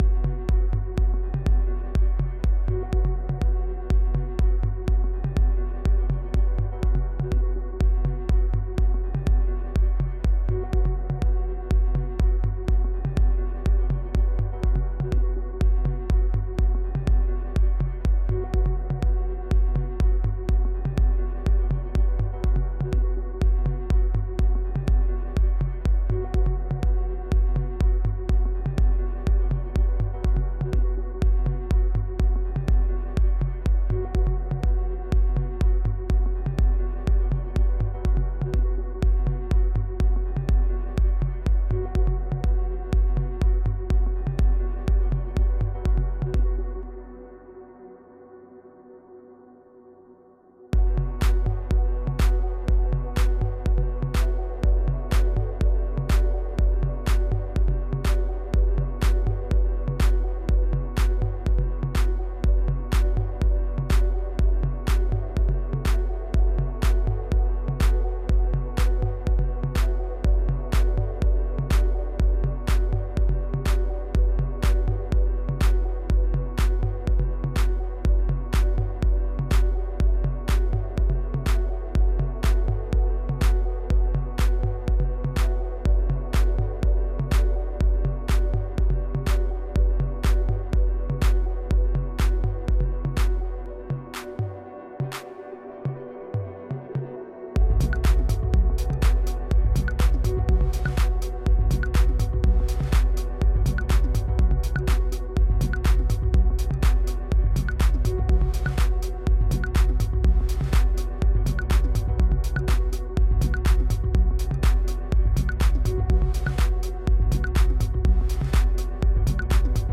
A Progressive House Journey